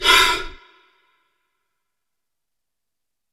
Heavy Breaths
BREATH4W-R.wav